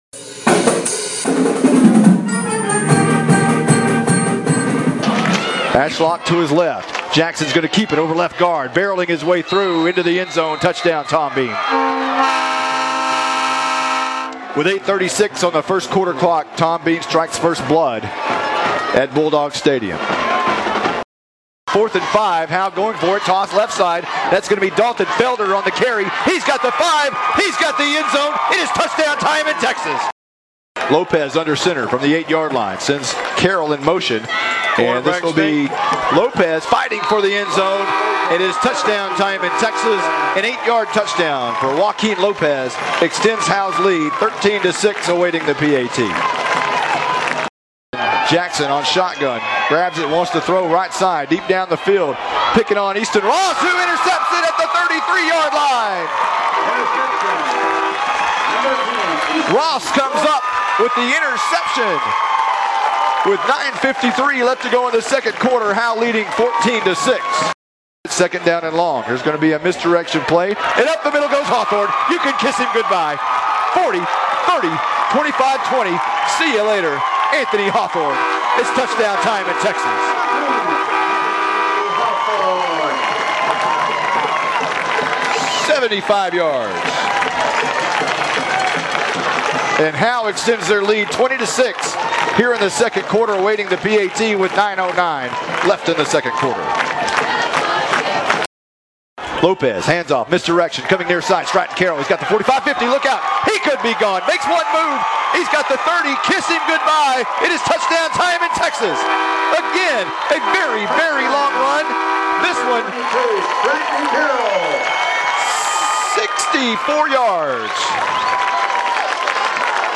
Post navigation Previous Post Previous Howe vs. Tom Bean broadcast highlights